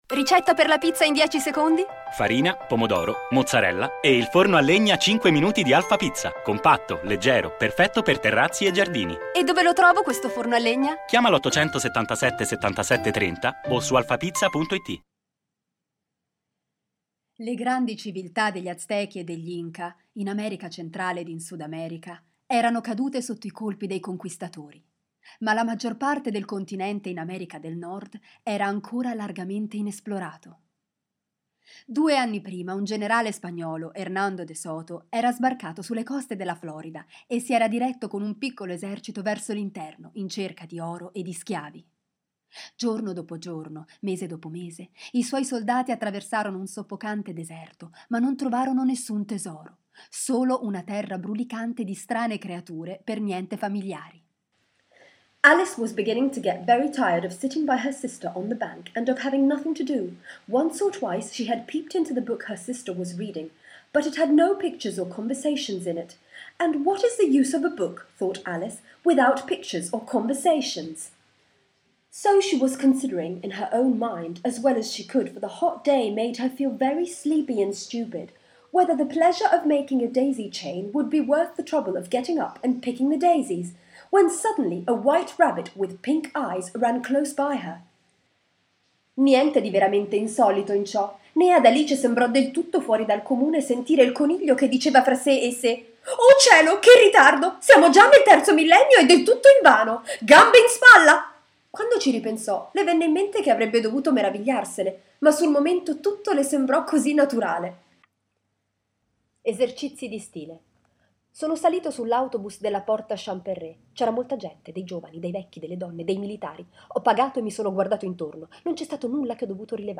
Kein Dialekt
Sprechproben: